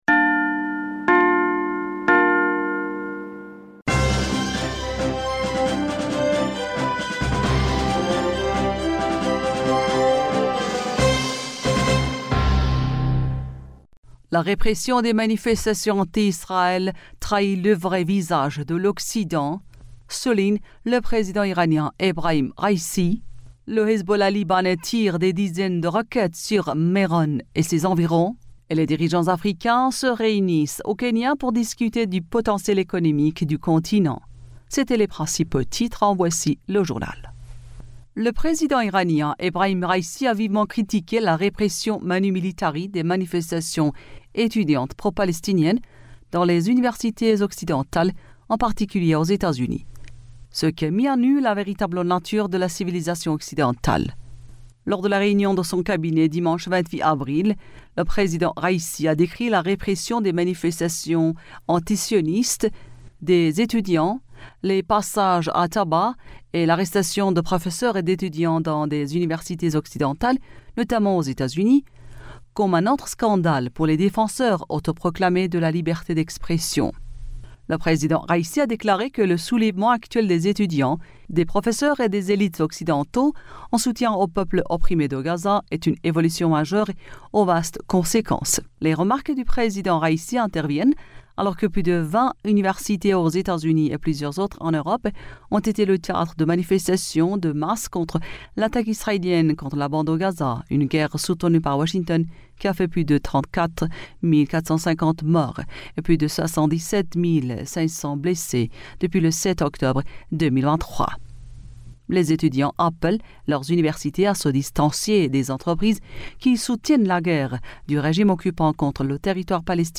Bulletin d'information du 29 Avril